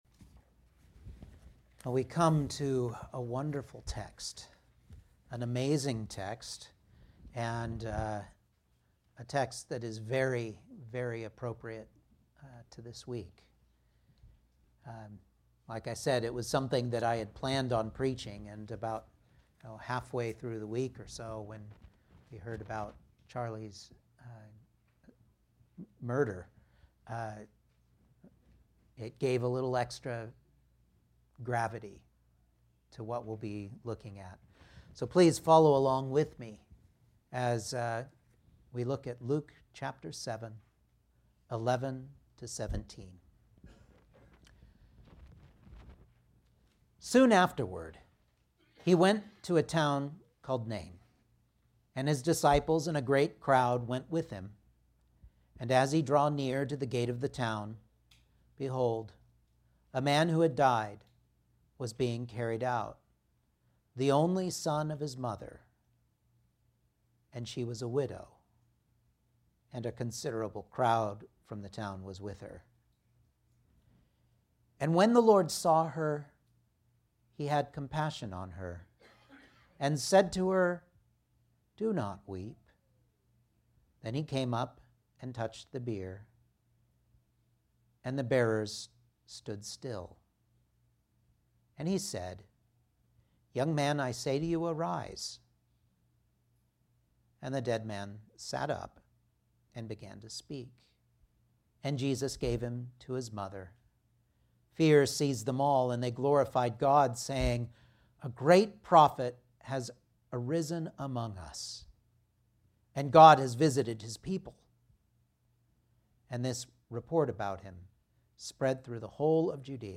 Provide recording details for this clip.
Luke 7:11-17 Service Type: Sunday Morning Outline